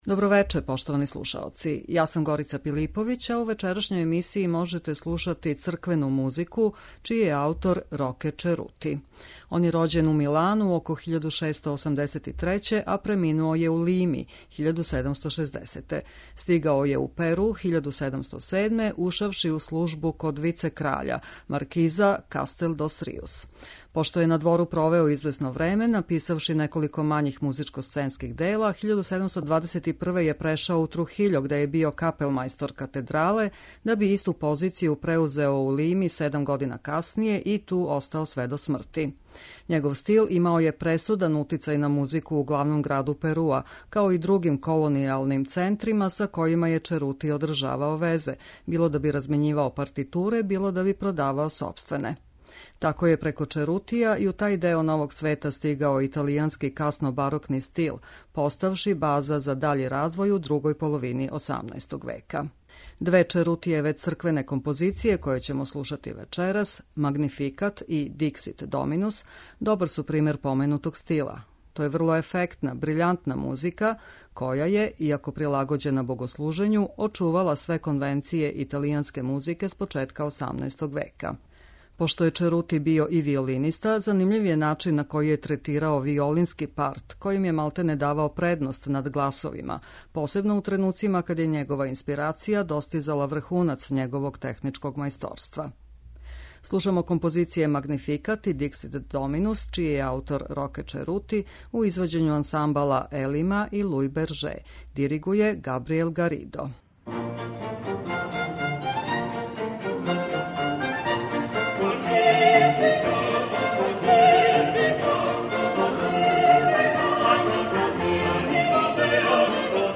Барокна музика Перуа